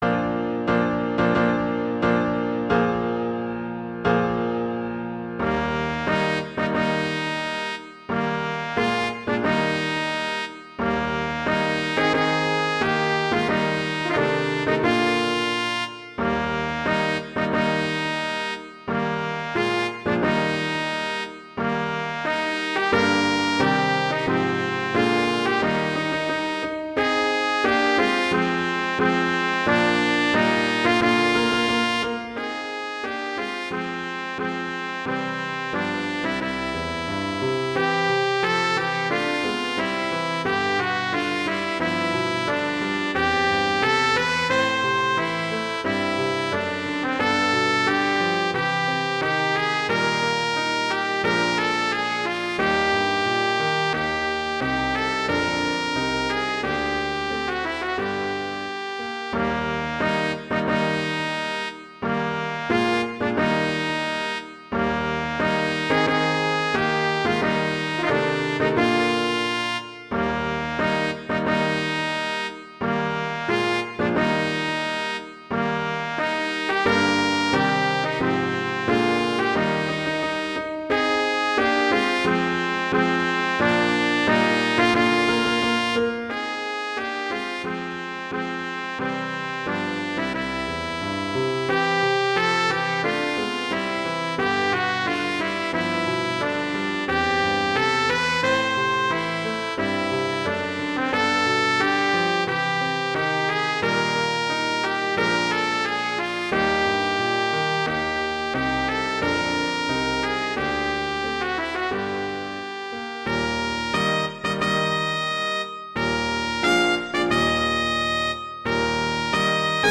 arrangements for trumpet and piano (organ)